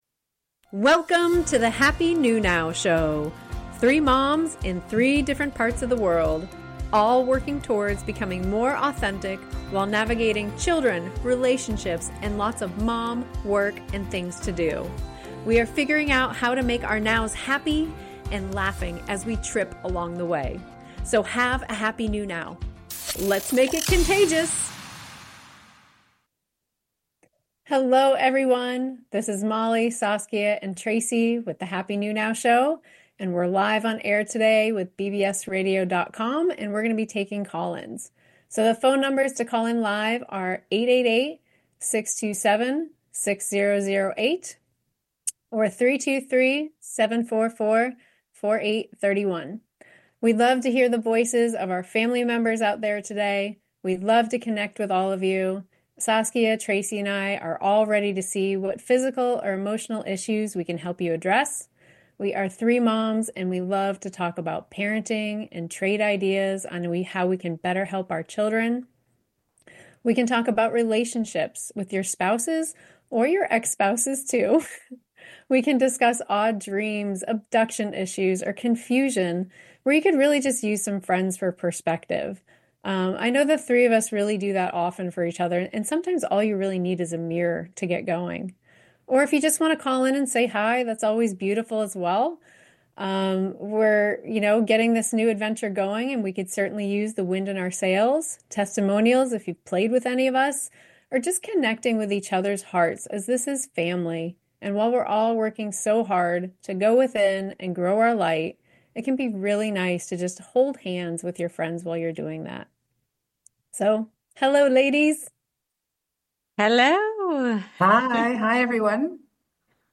Is Your Kid's Bad Mood Their Food? Guided Go Within Session and Call ins too, Ep 2, April 2, 2025